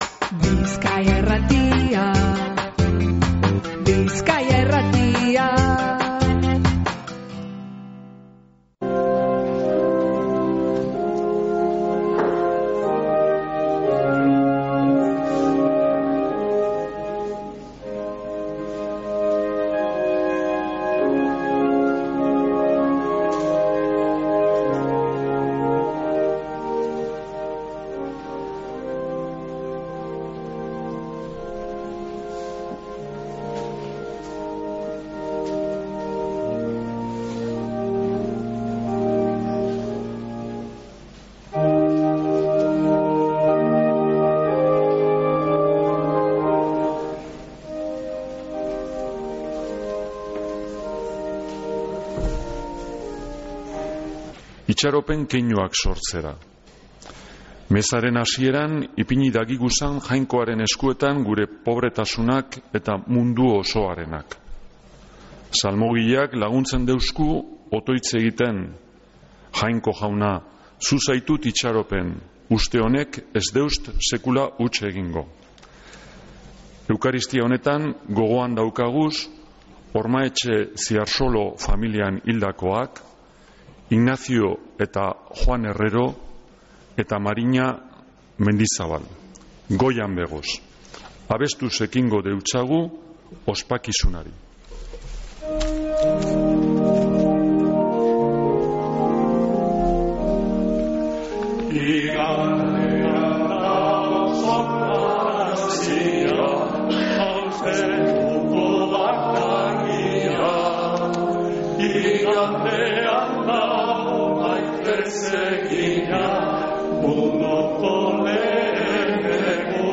Mezea San Felicisimotik | Bizkaia Irratia